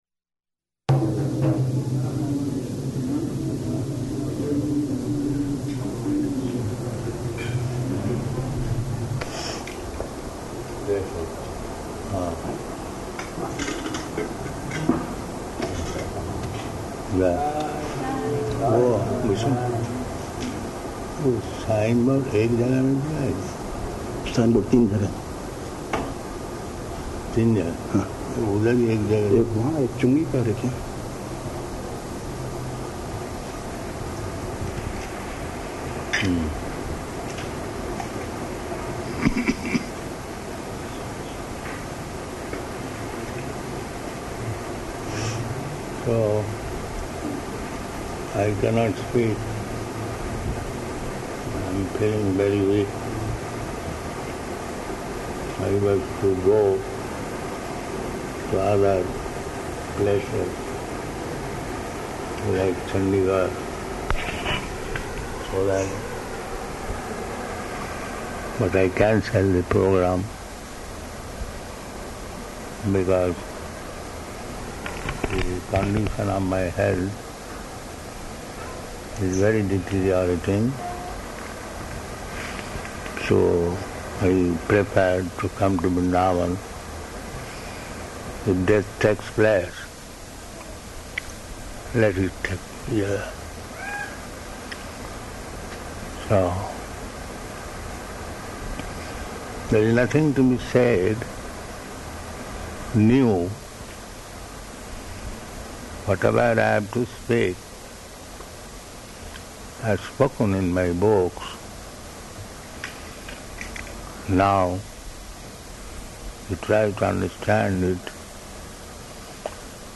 Arrival Speech
Location: Vṛndāvana